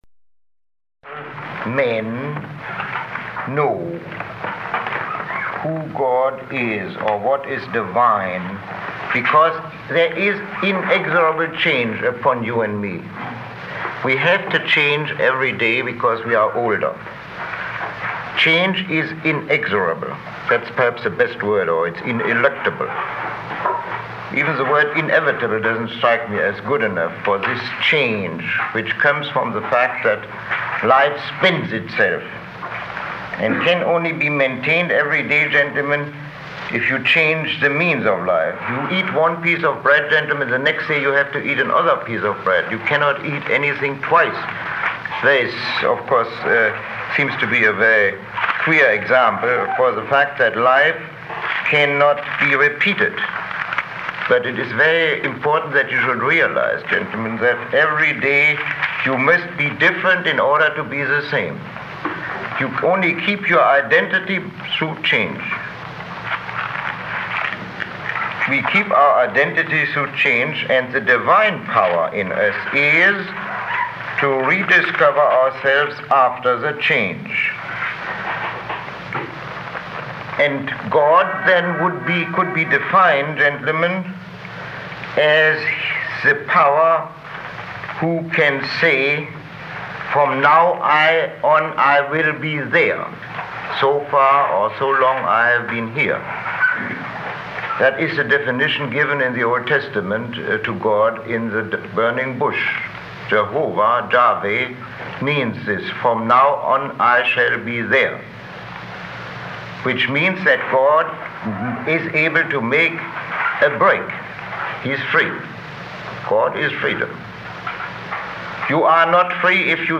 Lecture 09